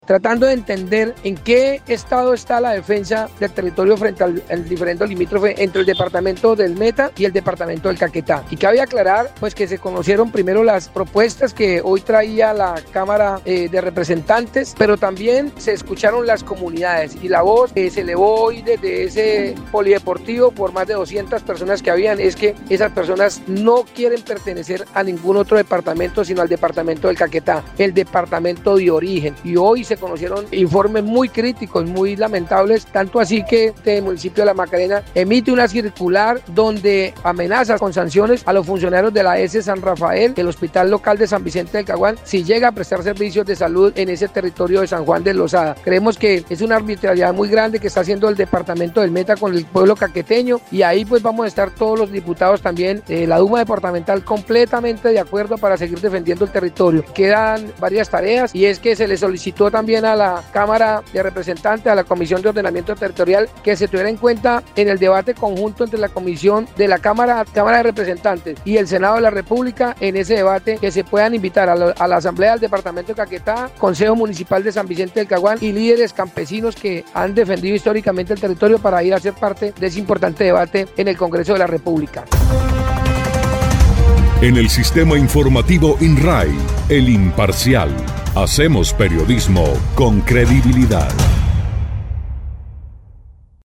Así lo dio a conocer el diputado sanvicentuno por Alianza Verde, Wilman Fierro Lugo, al manifestar que la denuncia se hizo pública el día anterior en medio de la audiencia pública donde se analizaba el diferendo limítrofe entre los dos departamentos, afectando el derecho a la salud de quienes habitan dicha zona.
02_DIPUTADO_WILMAN_FIERRO_DESLINDE.mp3